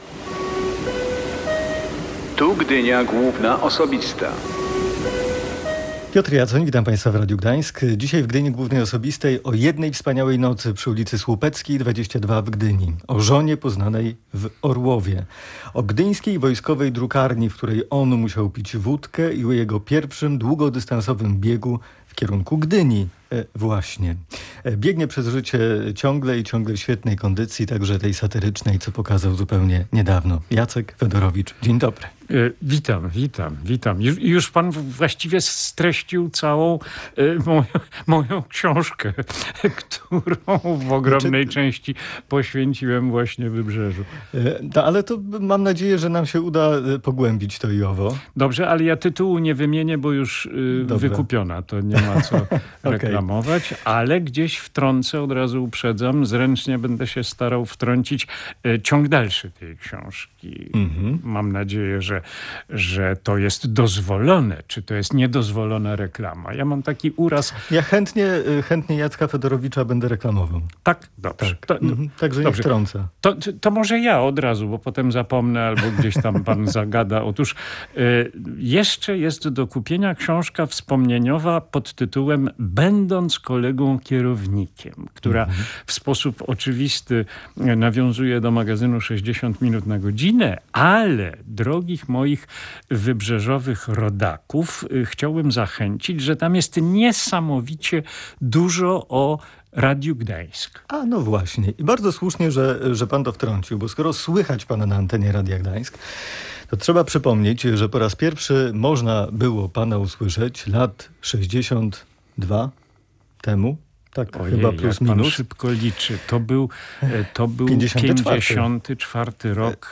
Gościem Piotra Jaconia w audycji Gdynia Główna Osobista był Jacek Fedorowicz.